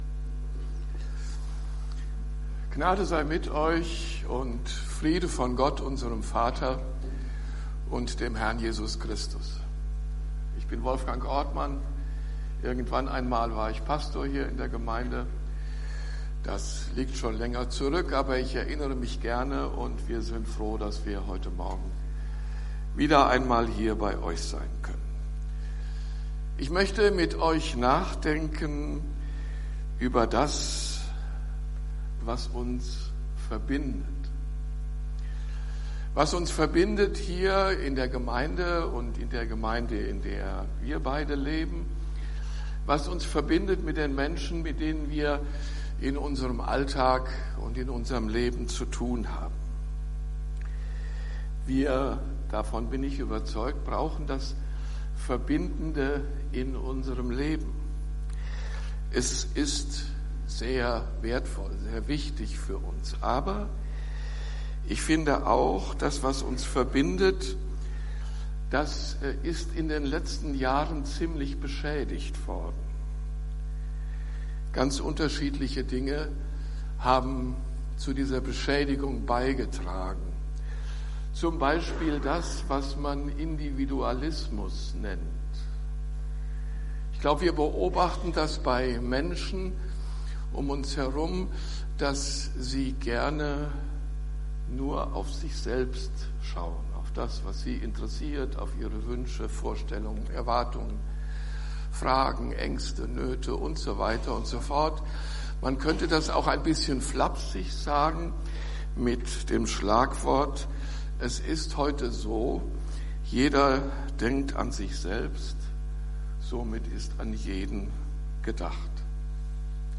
Predigt Podcast FeG Wuppertal Vohwinkel